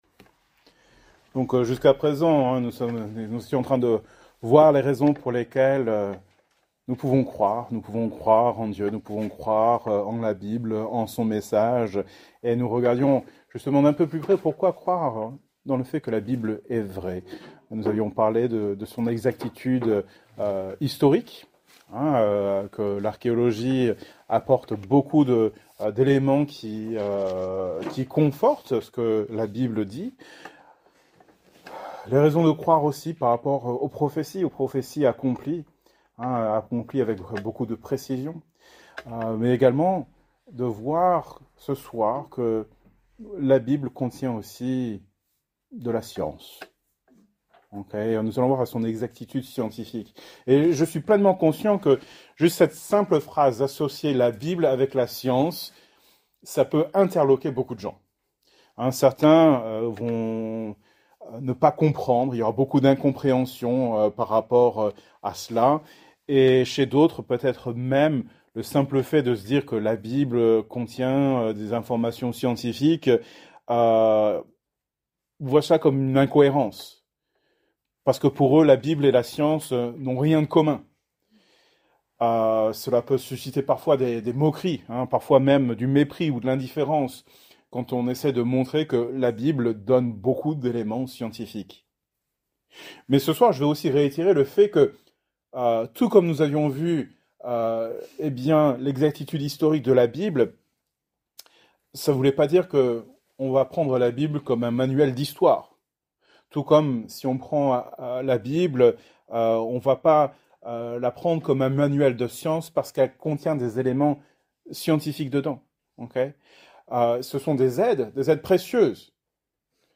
Thème: Apologétique , Foi Genre: Etude Biblique